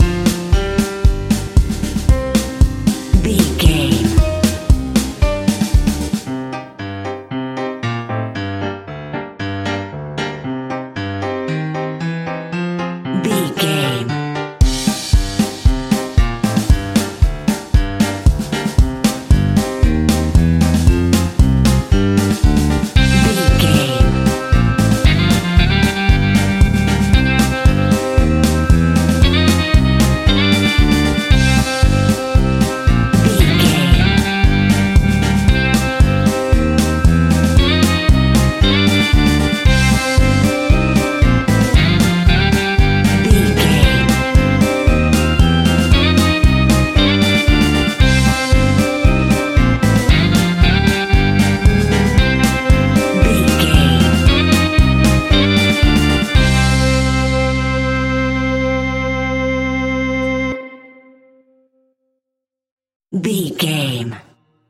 Aeolian/Minor
scary
ominous
dark
eerie
energetic
groovy
drums
electric guitar
bass guitar
piano
synthesiser
horror